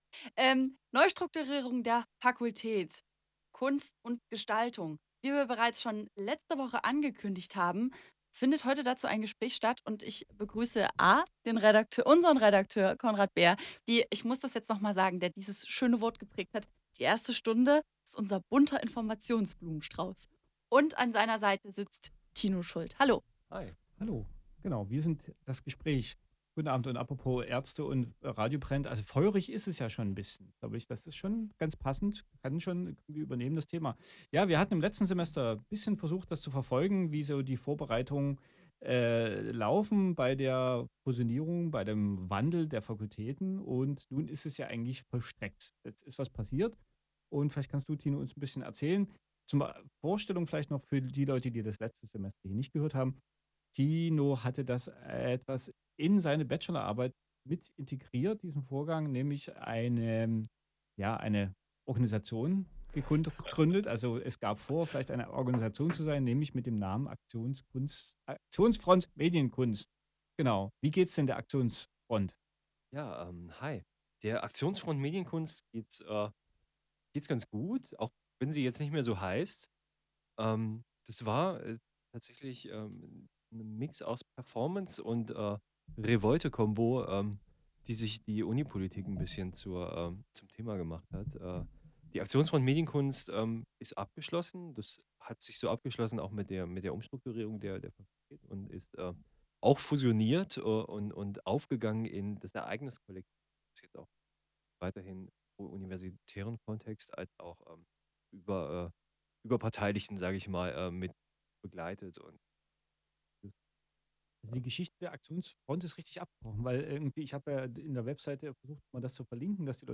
» Gespräch zum Wandel der Aktionsfront Medienkunst zum Ereignis Kollektiv